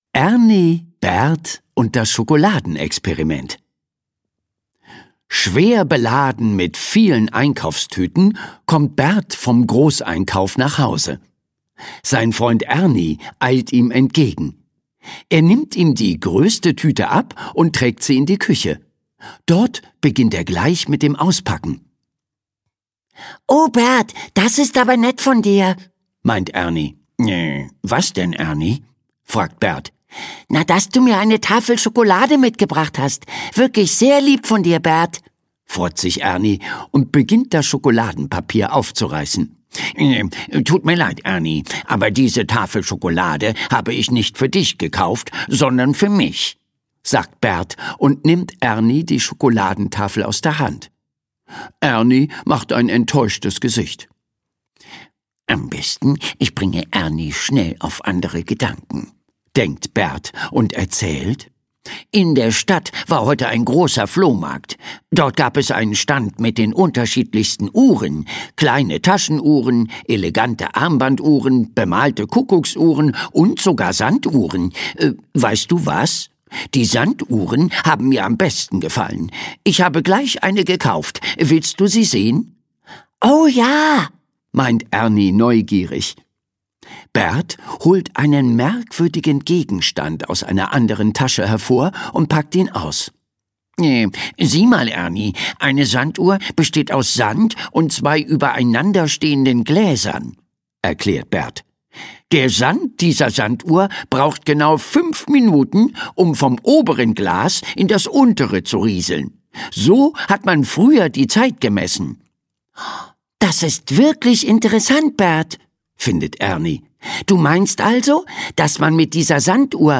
In der Sesamstraße ist immer was los: Da wird Samson gesund gepflegt, die Sesamstraßen-Freunde machen Urlaub am Meer, Grobi findet einen Freund zum Spielen, Ernie und Bert experimentieren mit Schokolade ... Diese und viele weitere fröhliche Geschichten aus dem Sesamstraßen-Alltag erzählt die Stimme vom liebenswerten Grobi.
Schlagworte Grobi • Hörbuch; Hörspiel für Kinder/Jugendliche • Hörbuch; Lesung für Kinder/Jugendliche • Kinder • Kinder, Lesung, Grobi • Lesung • Sesamstraße; Kinder-/Jugendlit.